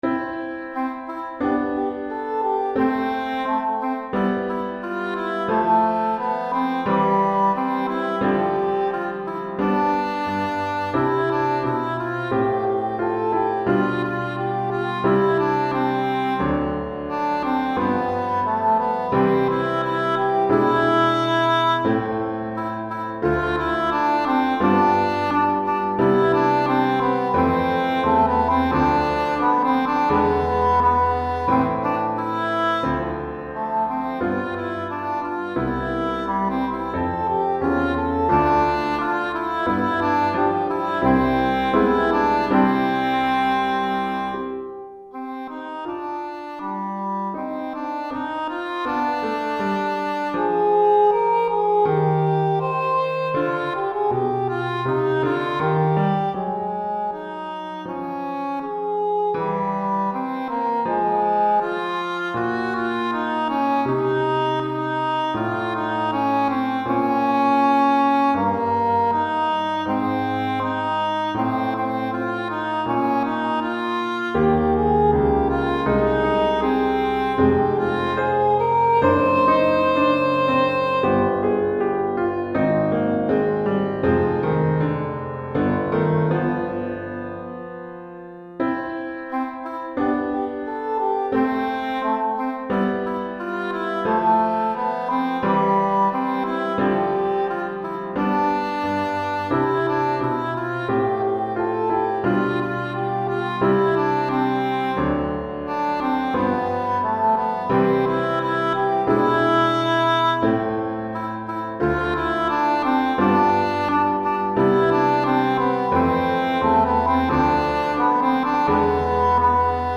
Répertoire pour Hautbois - Cor Anglais et Piano